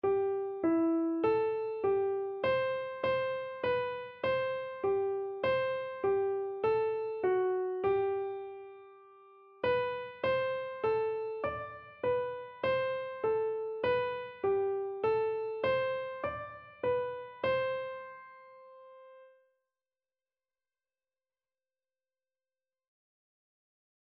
Free Sheet music for Keyboard (Melody and Chords)
4/4 (View more 4/4 Music)
C major (Sounding Pitch) (View more C major Music for Keyboard )
Keyboard  (View more Easy Keyboard Music)
Classical (View more Classical Keyboard Music)